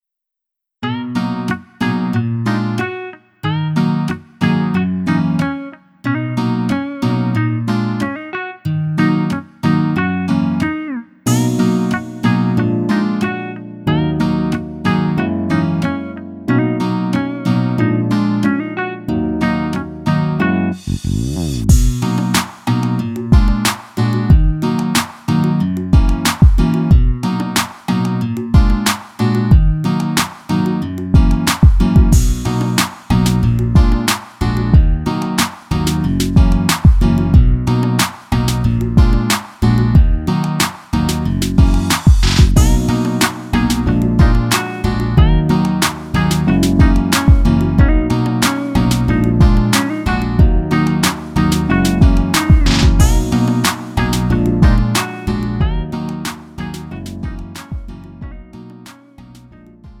음정 -1키 3:53
장르 가요 구분